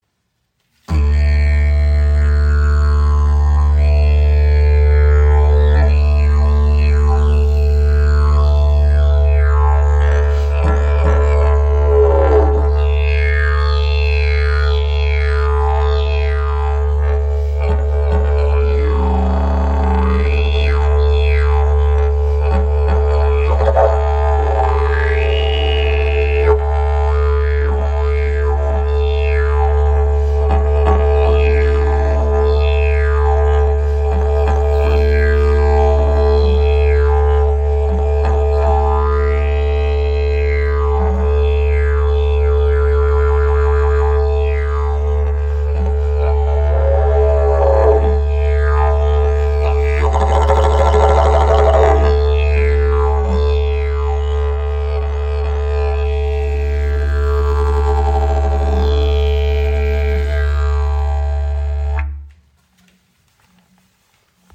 Didgeridoo | D im Raven-Spirit WebShop • Raven Spirit